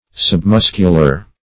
Submuscular \Sub*mus"cu*lar\, a.